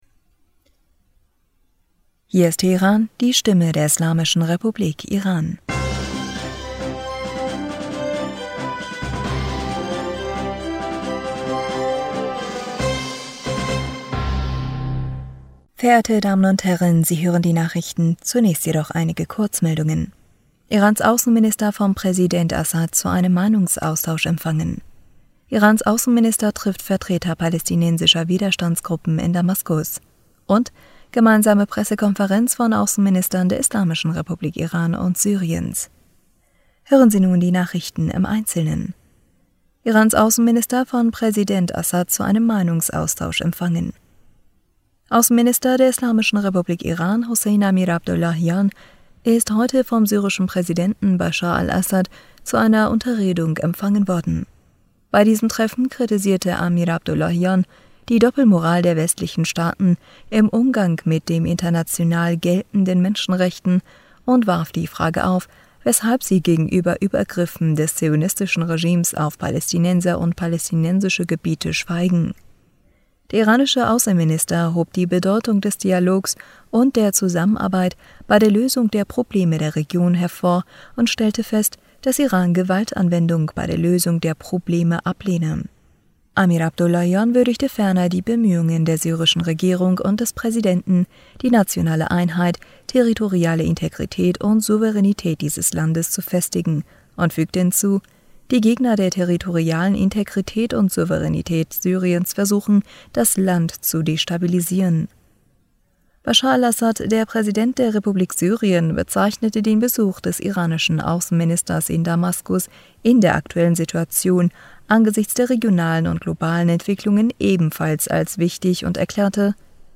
Nachrichten vom 3. Juli 2022